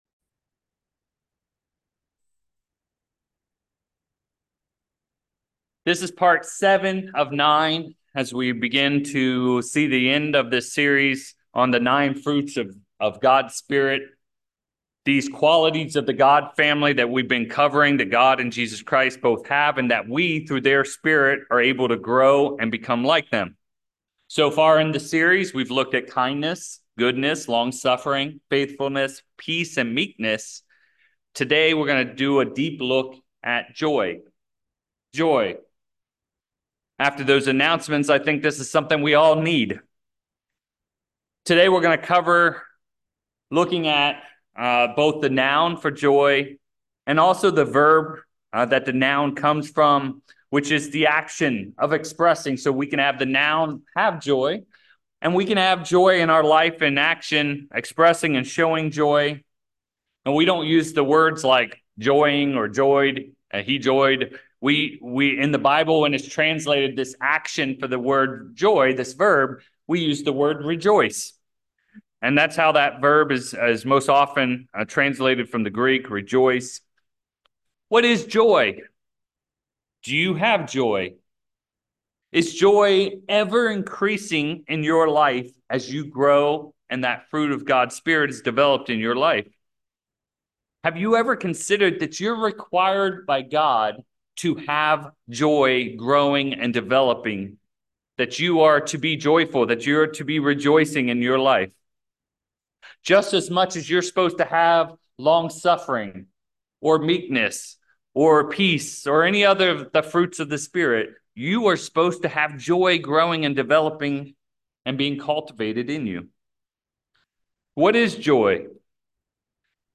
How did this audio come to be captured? Given in Petaluma, CA San Francisco Bay Area, CA